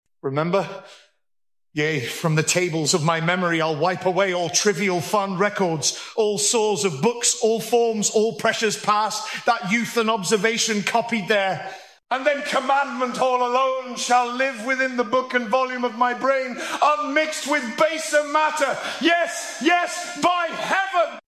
MiniMax Speech 2.5提高了生成音频的相似度和自然韵律度，降低了字错率、减小了AI生成的商务会议、日常对话、英文播客的机械感。
立下复仇誓言的哈姆雷特